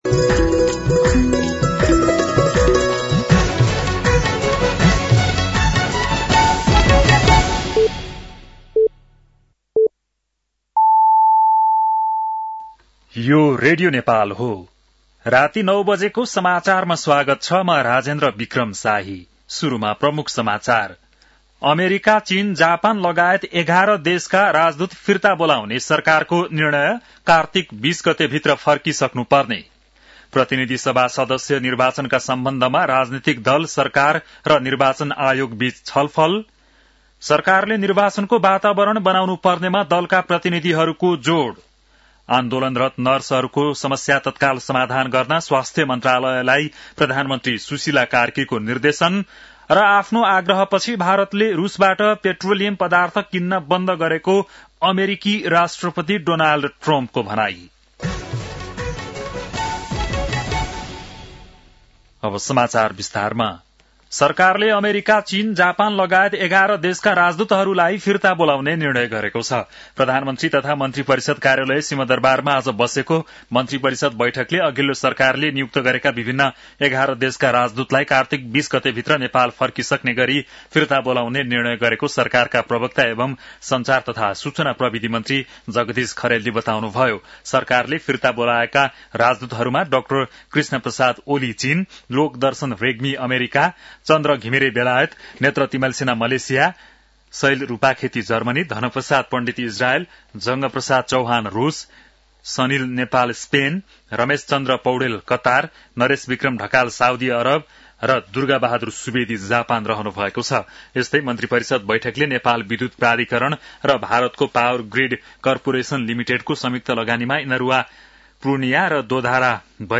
बेलुकी ९ बजेको नेपाली समाचार : ३० असोज , २०८२
9-PM-Nepali-NEWS-1-1.mp3